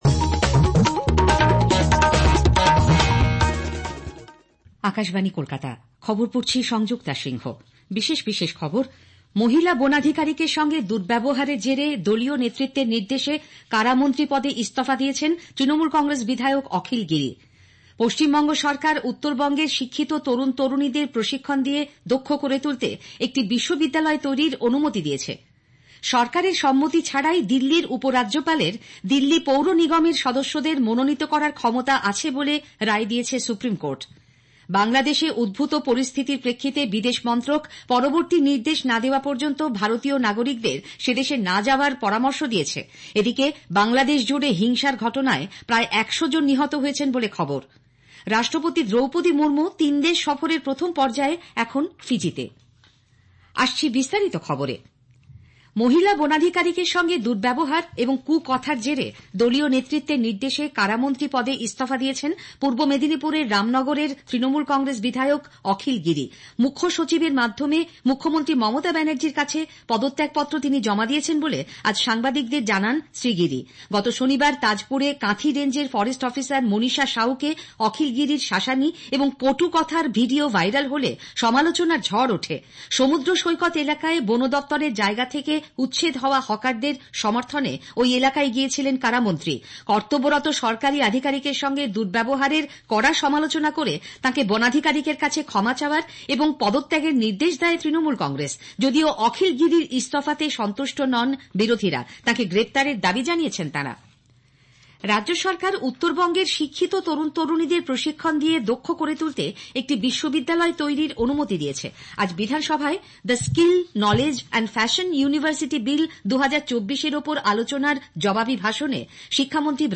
Transcript summary Play Audio Midday News